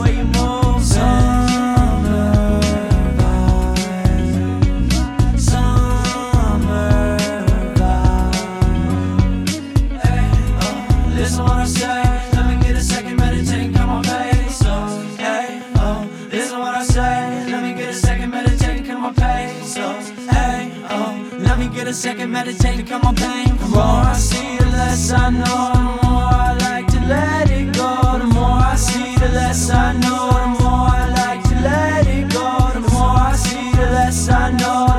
Жанр: Рок
# Rock